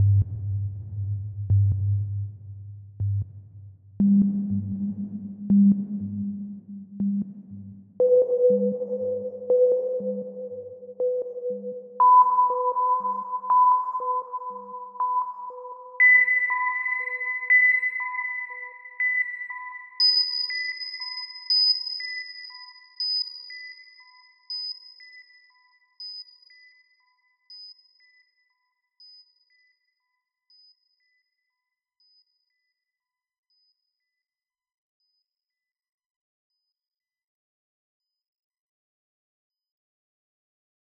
Atlas - STest1-PitchPulse-Right-100,200,500,1000,2000,5000.flac